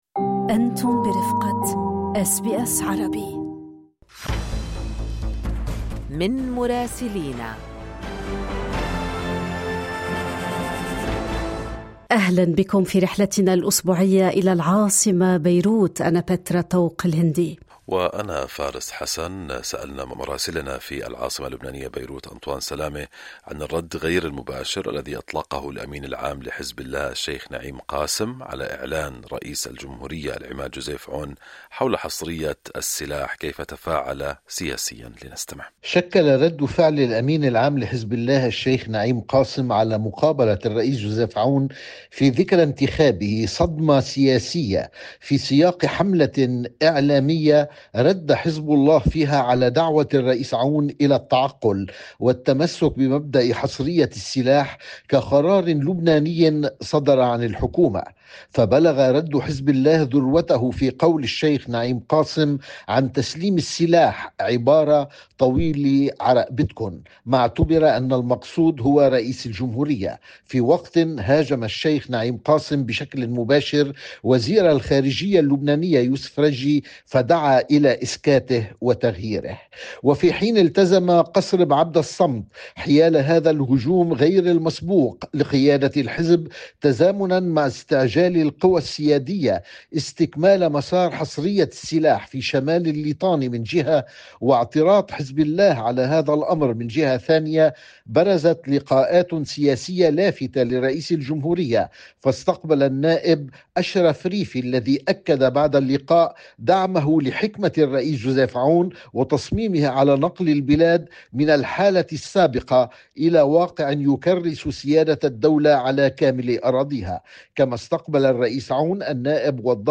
في تقريرنا الأسبوعي من العاصمة اللبنانية بيروت، برزت تطورات لافتة على الساحة الداخلية تتعلق بملف حصرية السلاح والعلاقات الإقليمية والدولية، إضافة إلى مبادرة ثقافية ذات طابع وطني.